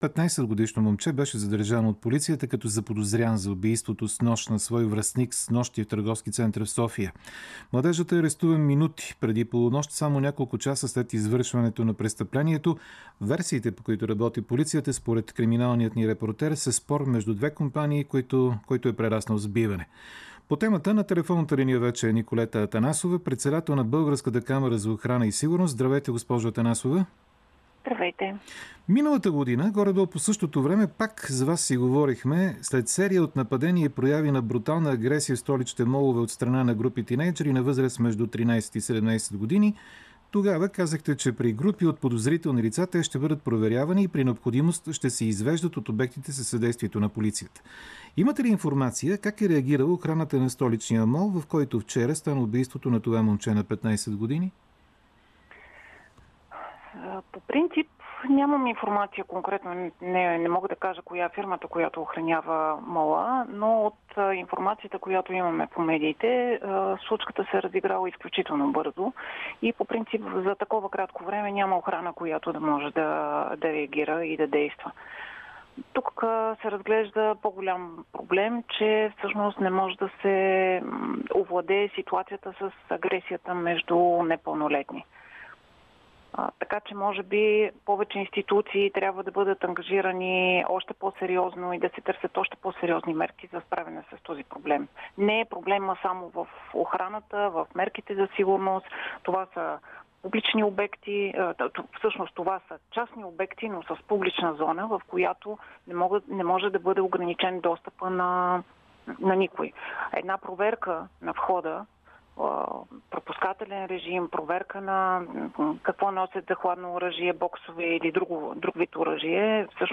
Интервюто